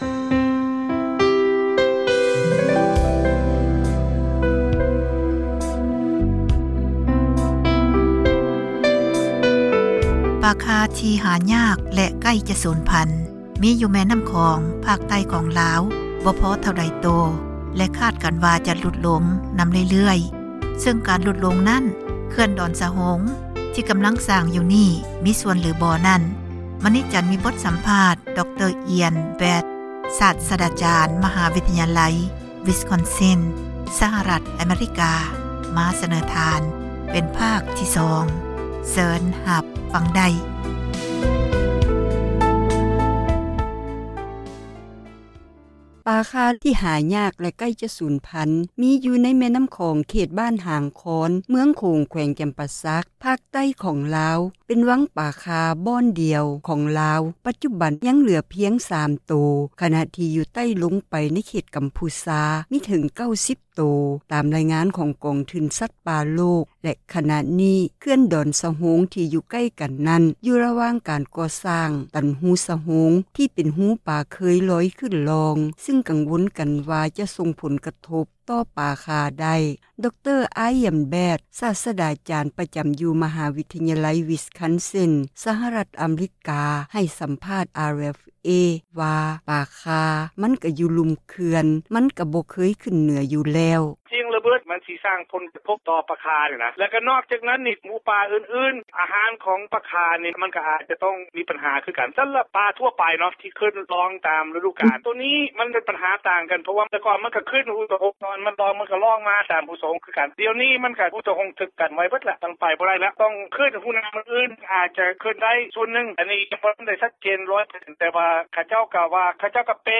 (ສຽງສັມພາດ)